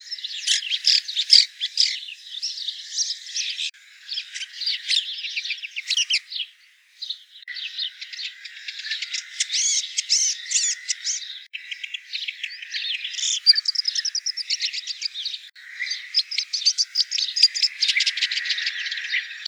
Lanius collurio - Red-backed shrike - Averla piccola
- POSITION: Poderone near Magliano in Toscana, LAT.N 42 36'/LONG.E 11 17'- ALTITUDE: +130 m. - VOCALIZATION TYPE: full song.
Far background: tractor engine.